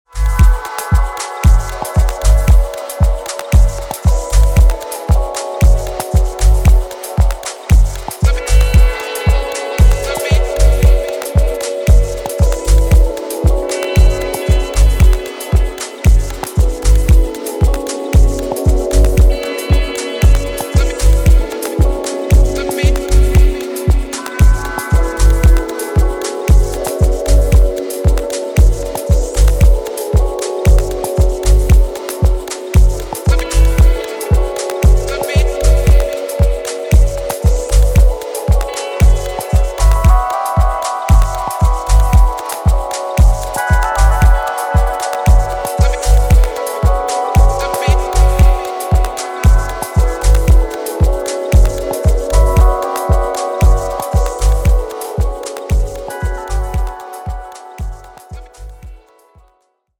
諸要素を絞り、音響のサイケデリックな効果に注力したミニマル・ハウスのモダンな最新形。